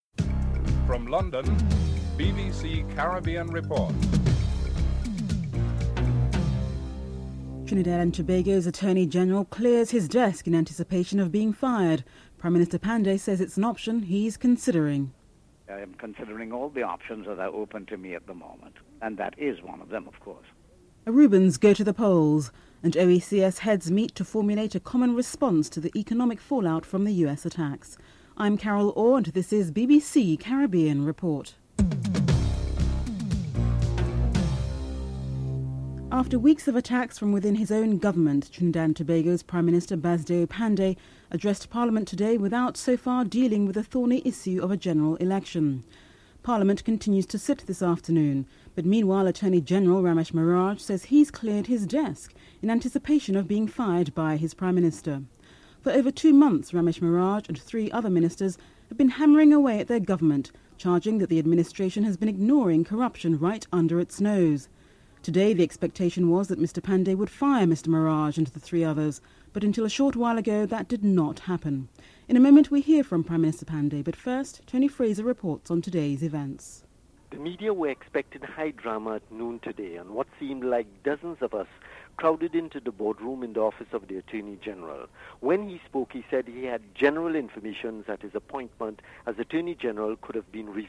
1. Headlines (00:00-00:34)